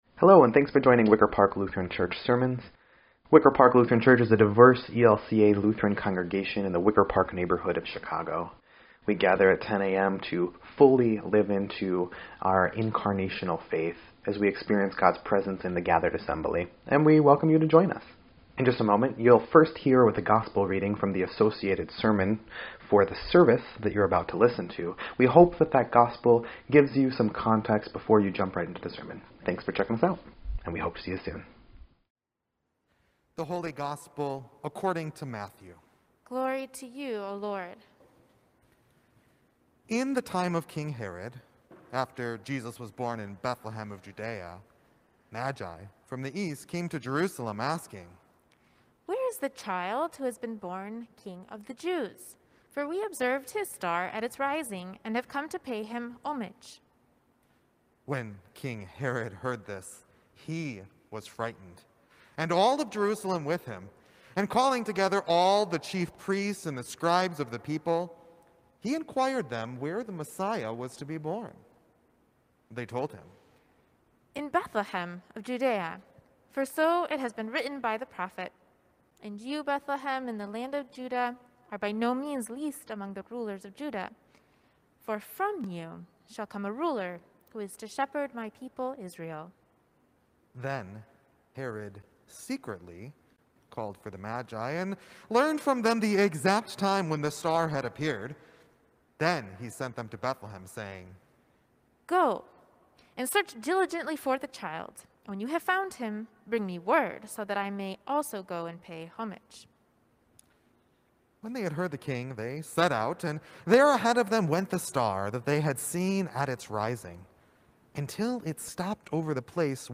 1.10.21-Sermon_EDIT.mp3